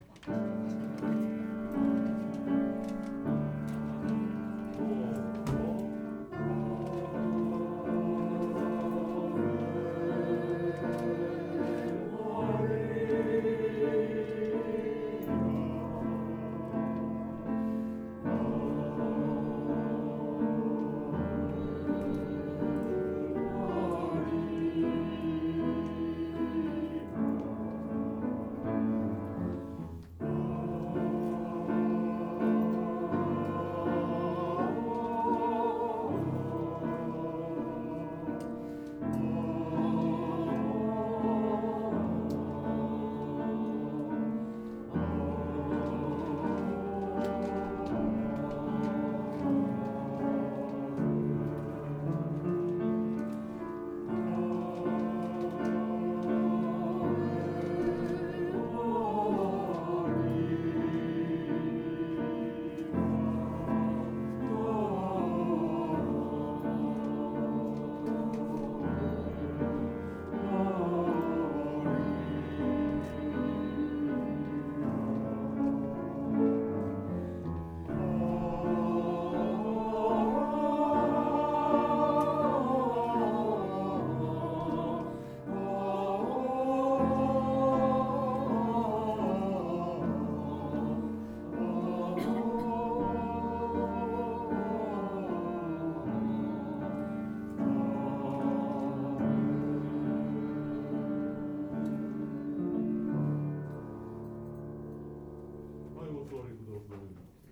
練習場所：アスピア明石北館　8階学習室801A・B（明石市）
・体操、発声、発声練習用 Ave Maria（ウラジミール・ヴァヴィロフ作曲）▶録音（Sop・Ten）